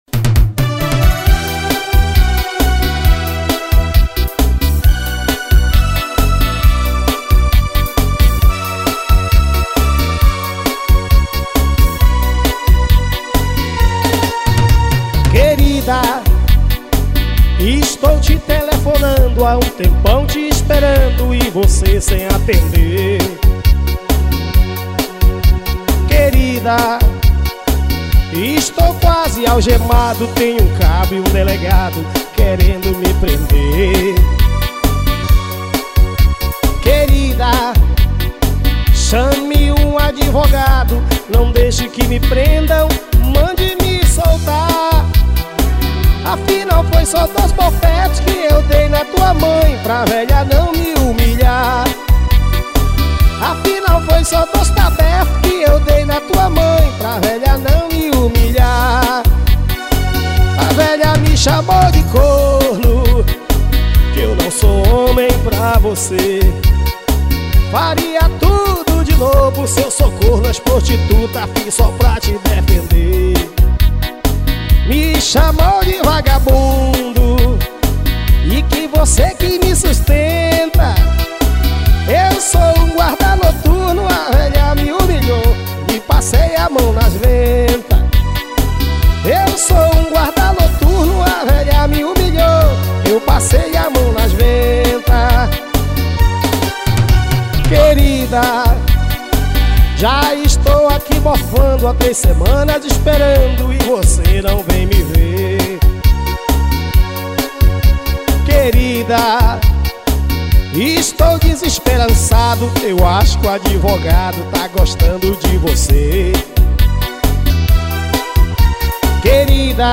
AO VIVO MANAUS.